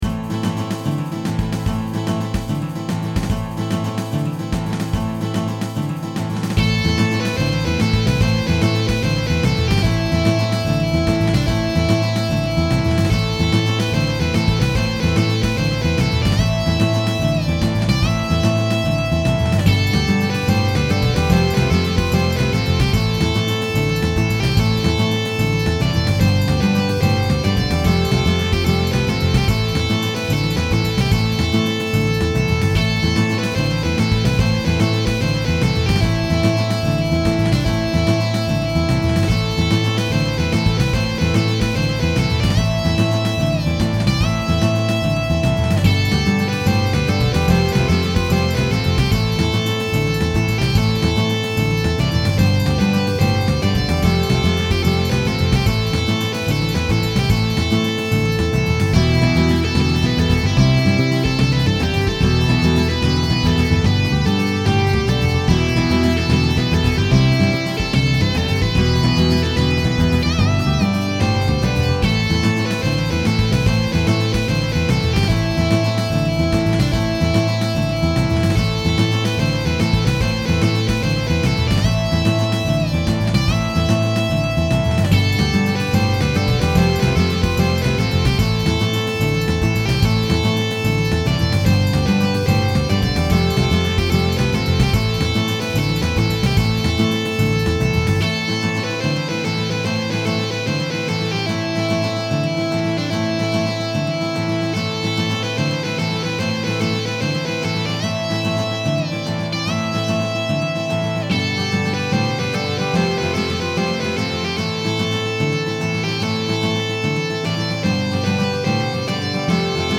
Now I want to be clear, these are not finished songs.
The album itself is going to be quite eclectic in style - some tracks traditional, others heavily electronic.